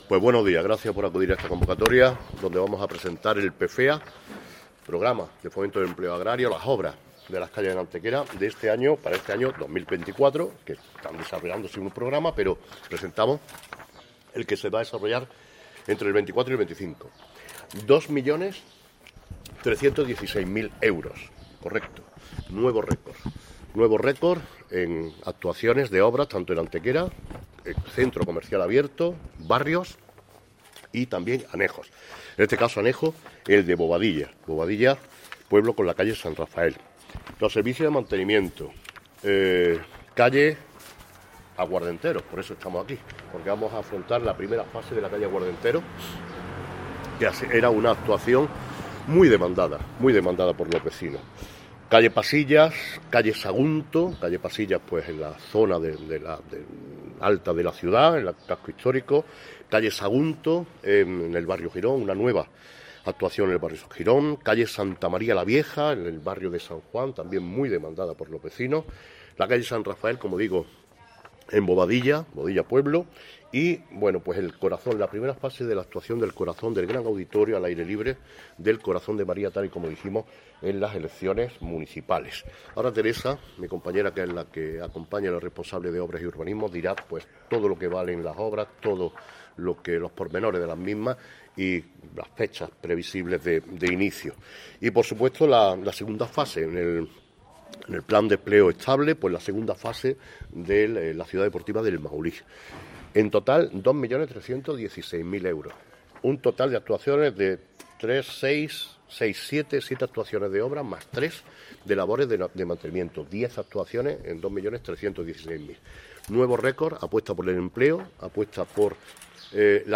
El alcalde de Antequera, Manolo Barón, y la teniente de alcalde delegada de Obras y Mantenimiento, Teresa Molina, han presentado en rueda de prensa los proyectos que formarán parte de las obras PFEA en su anualidad 2024/2025, con la pretensión de que puedan iniciarse, tras las preceptivas licitaciones aparejadas, en el último trimestre del año.
Cortes de voz